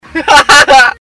Laugh 25